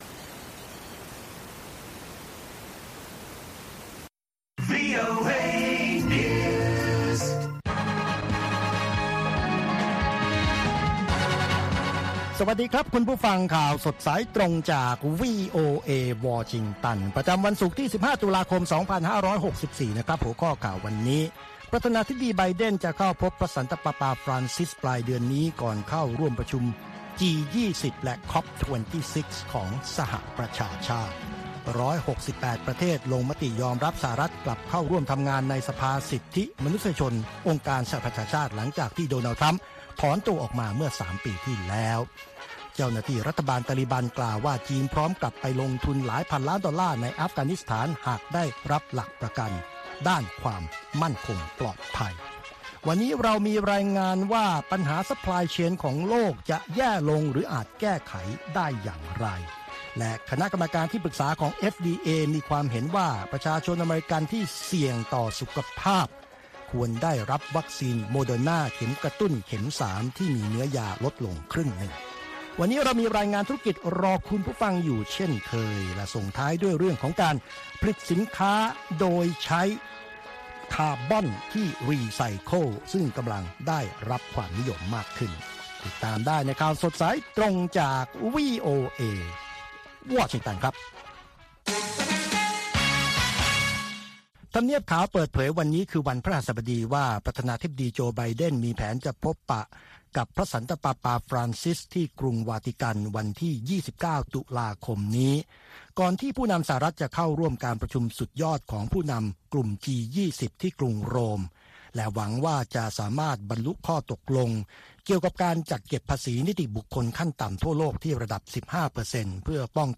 ข่าวสดสายตรงจากวีโอเอ ภาคภาษาไทย 8:30–9:00 น. ประจำวันศุกร์ที่ 15 ตุลาคมตามเวลาในประเทศไทย